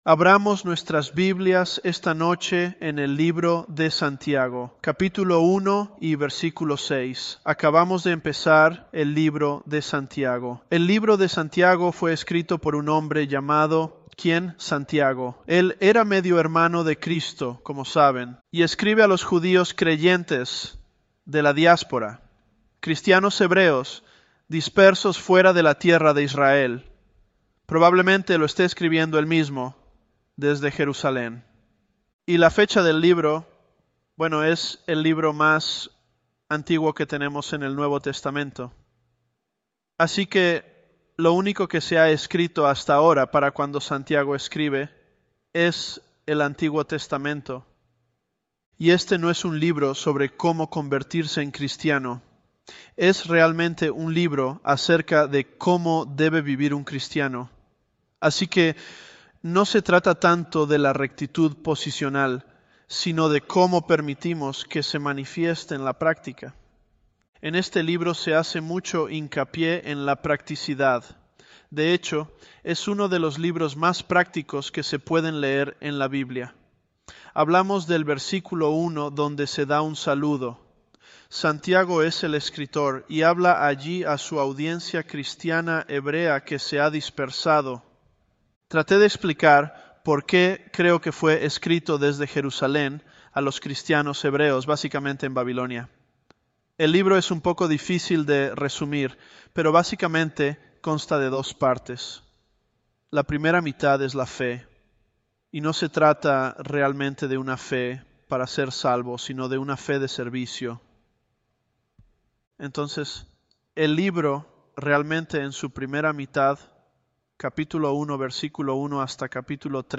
Sermons
Elevenlabs_James004.mp3